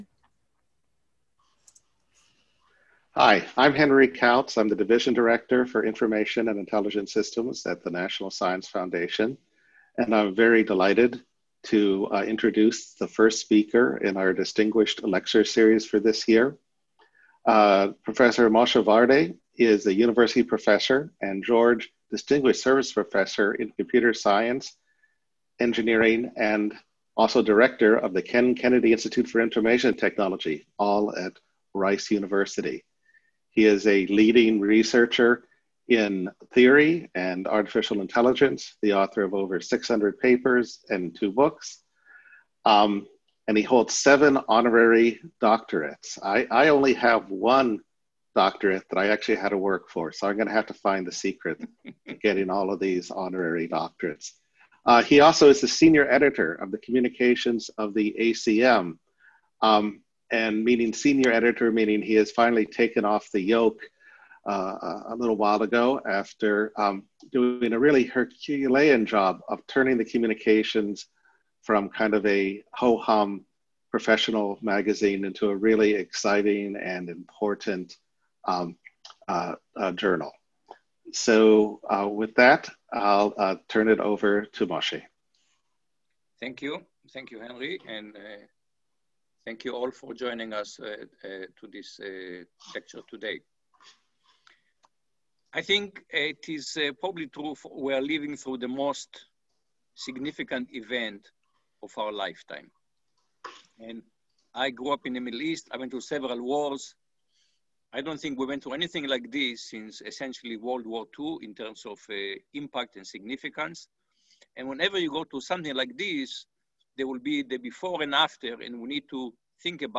CISE Distinguished Lecture - September 17, 2020